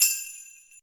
soft-hitwhistle.ogg